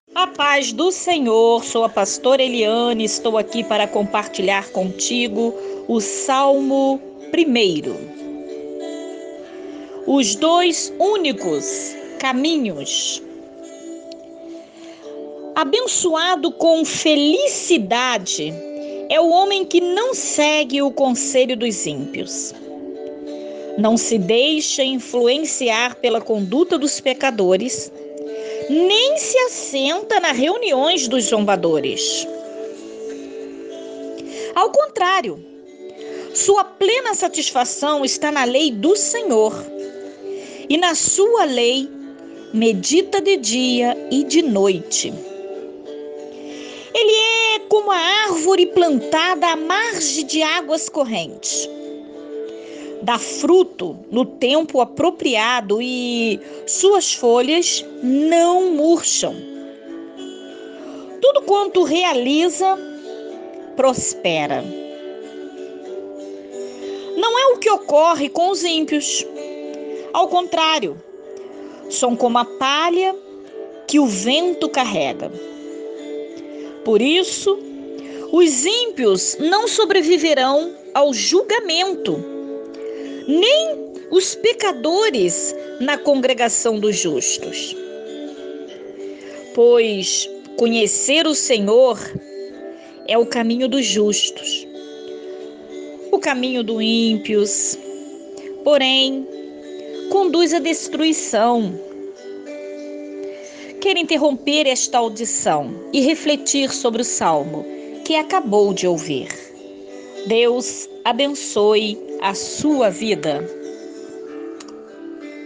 Lendo da Bíblia sagrada.